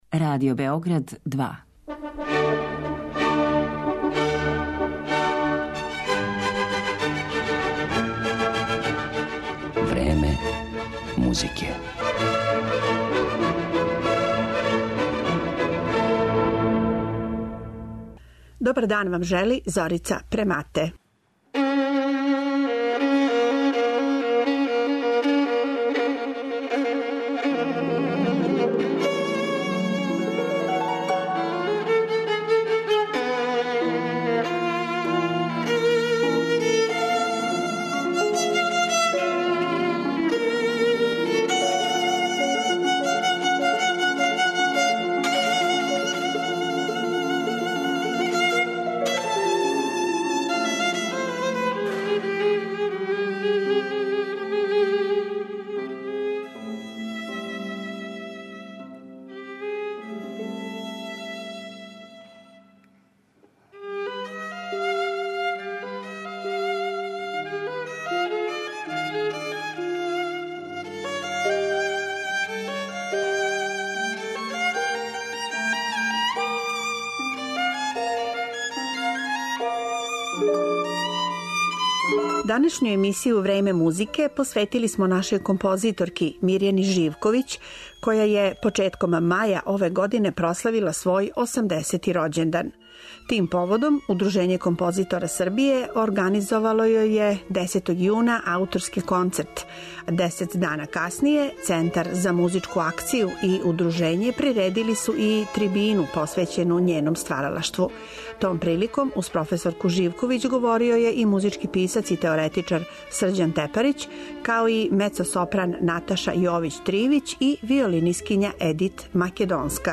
Удружење композитора Србије организовало јој је јубиларни ауторски концерт, а крајем јуна одржана је и трибина посвећена њеном стваралаштву и педагошком деловању.